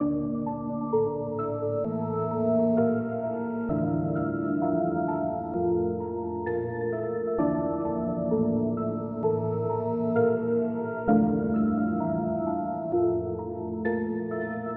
808黑手党类型的钢琴
Tag: 130 bpm Trap Loops Piano Loops 2.49 MB wav Key : Unknown FL Studio